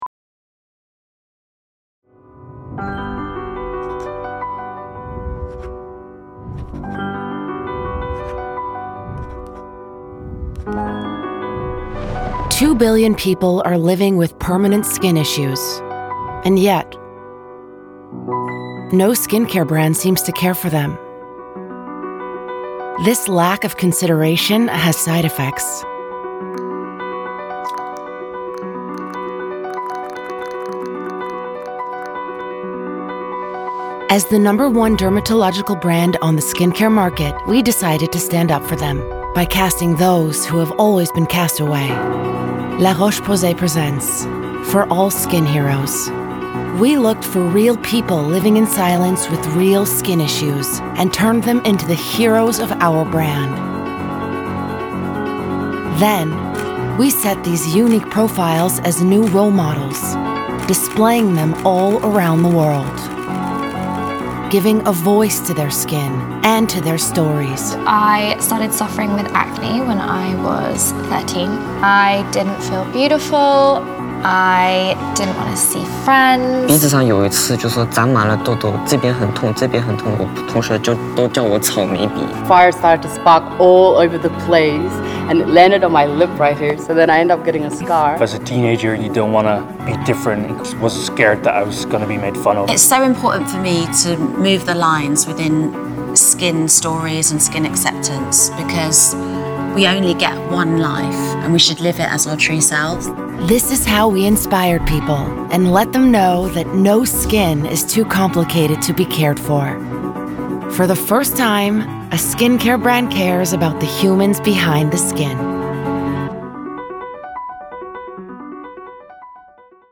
Female
Approachable, Assured, Confident, Conversational, Corporate, Energetic, Engaging, Natural
Microphone: Rode Nt1-A